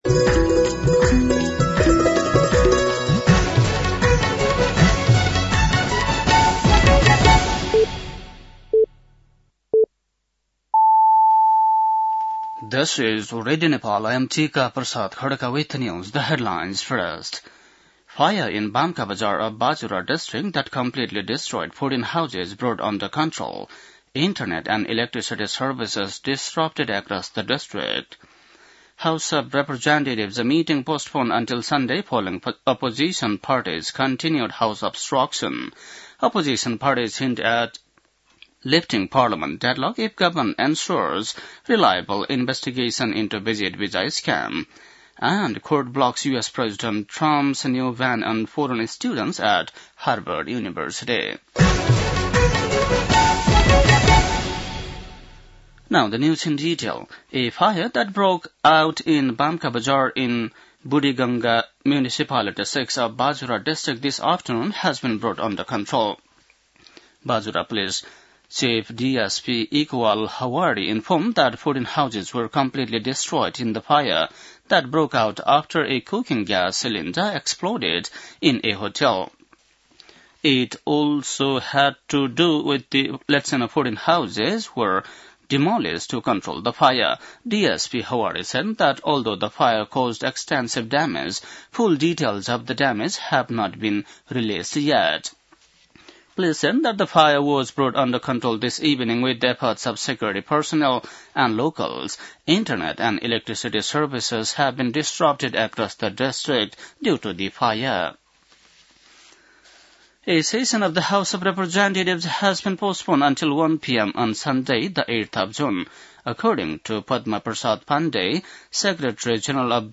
बेलुकी ८ बजेको अङ्ग्रेजी समाचार : २३ जेठ , २०८२
8-PM-English-NEWS-2-23.mp3